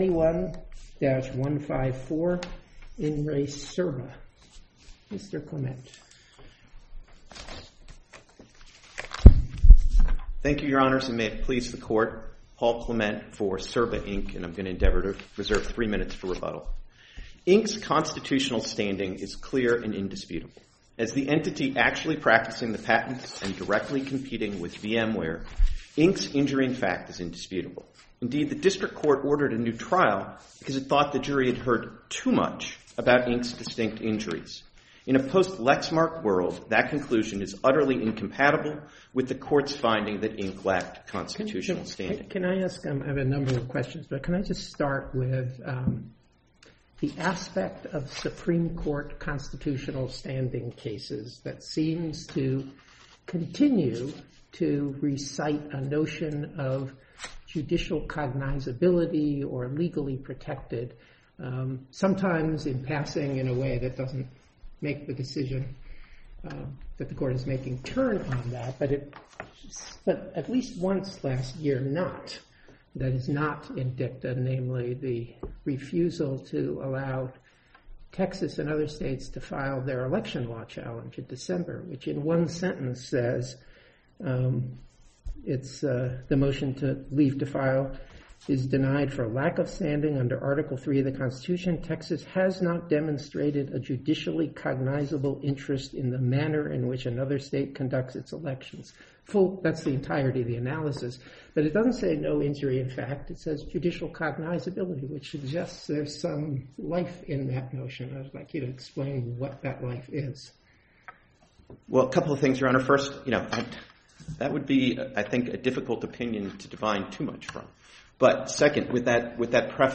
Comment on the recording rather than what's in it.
Oral Hearing - enhanced audio